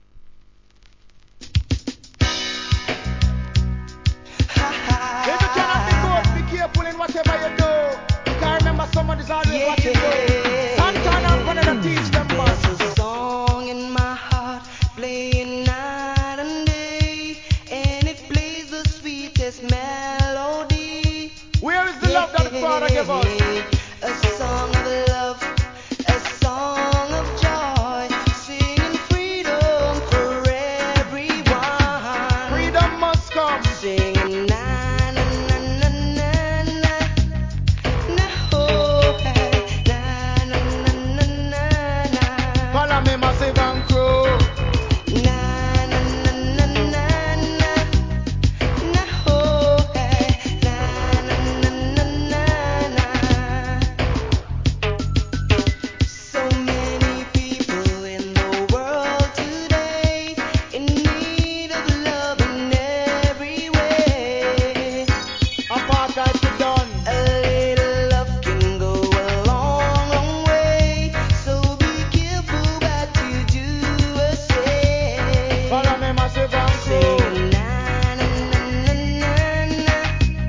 REGGAE
マイナー盤ながら、メロディアスなトラックで耳に残るフックにDeeJayが絡む好コンビネーション!!!